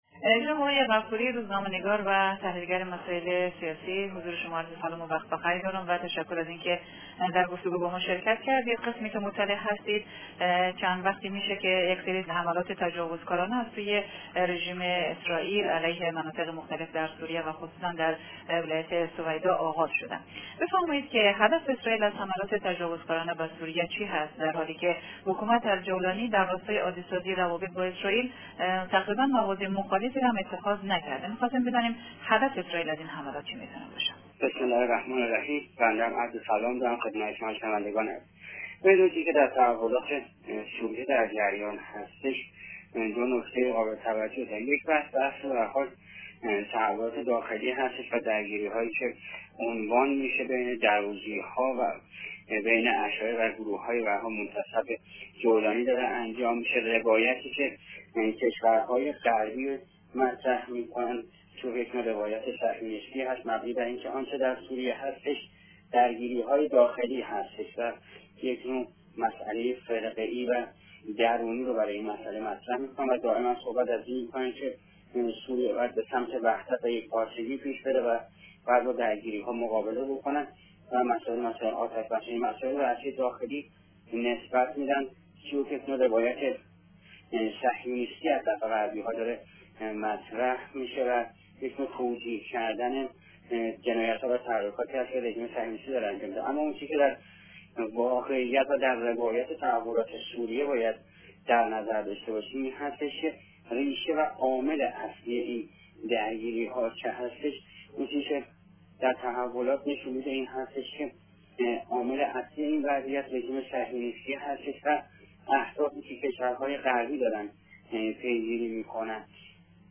کارشناس مسائل غرب آسیا، در گفت و گو با رادیو دری، رژیم صهیونیستی را عامل اصلی درگیری و نا آرامی های اخیر سوریه دانست و از جامعه جهانی خواست برای توقف این ب...